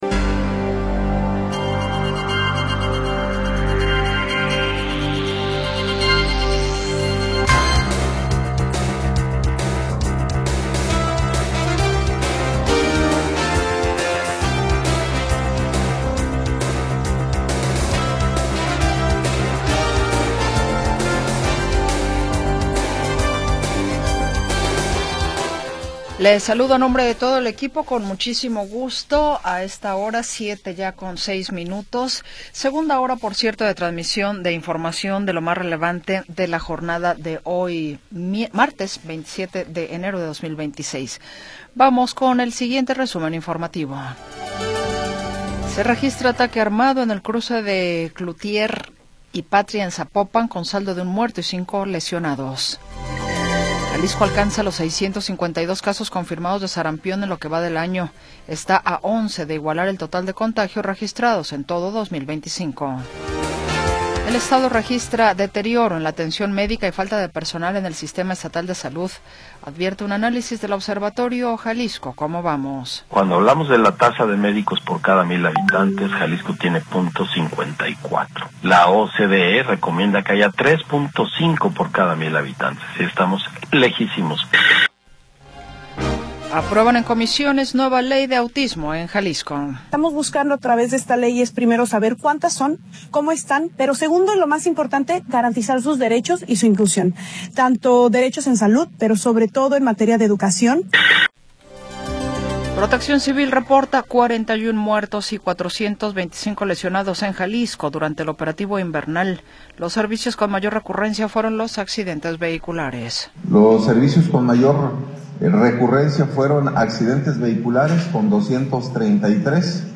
Segunda hora del programa transmitido el 27 de Enero de 2026.